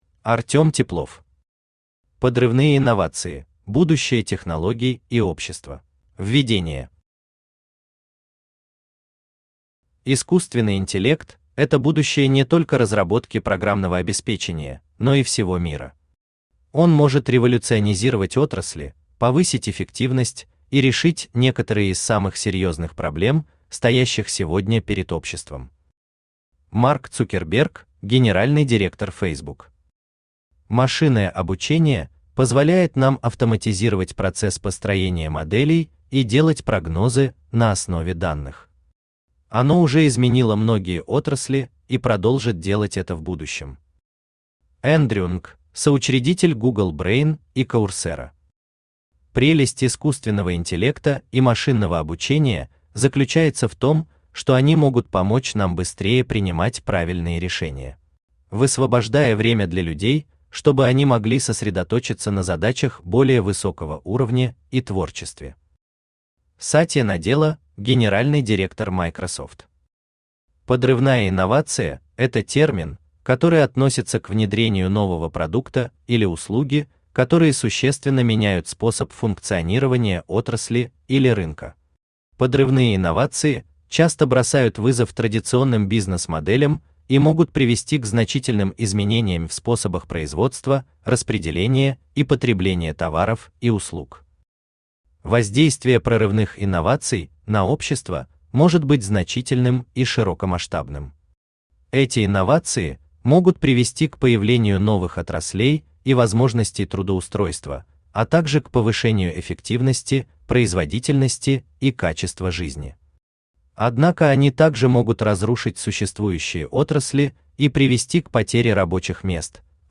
Аудиокнига Подрывные инновации: будущее технологий и общества | Библиотека аудиокниг
Aудиокнига Подрывные инновации: будущее технологий и общества Автор Артем Глебович Теплов Читает аудиокнигу Авточтец ЛитРес.